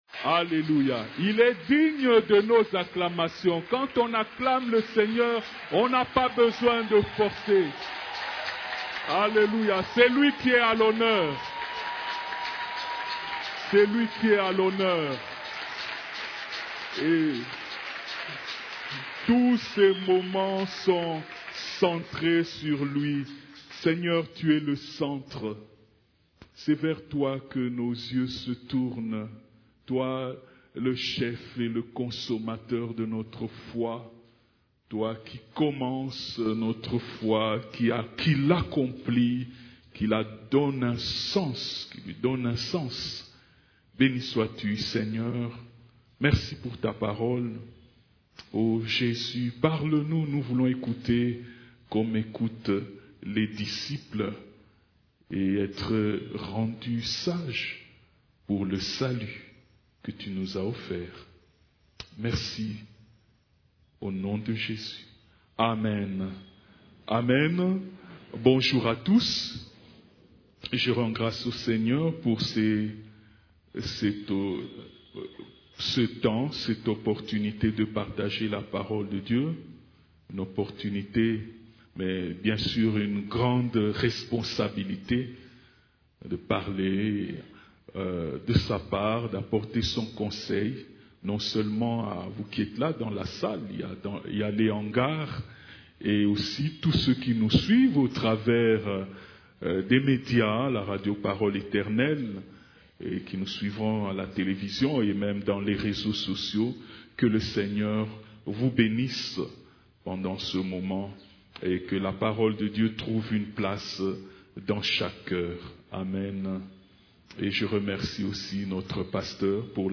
CEF la Borne, Culte du Dimanche, Quatre trésors de la sagesse divine